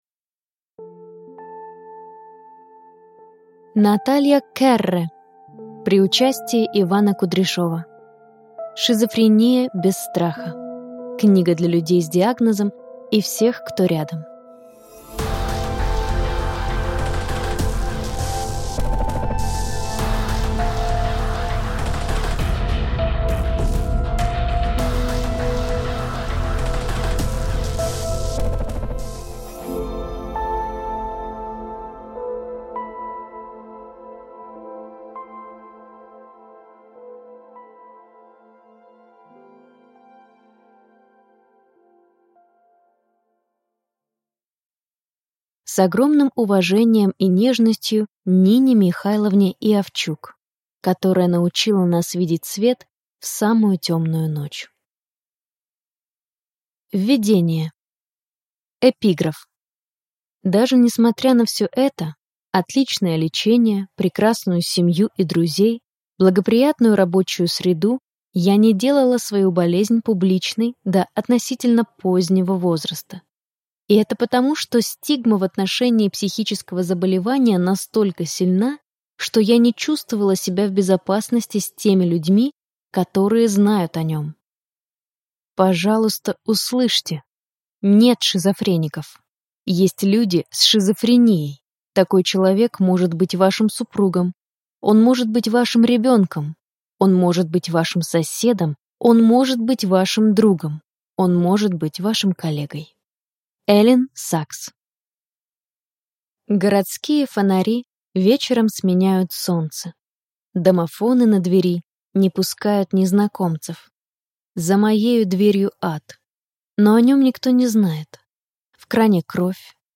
Аудиокнига Шизофрения без страха: Книга для людей с диагнозом и всех, кто рядом | Библиотека аудиокниг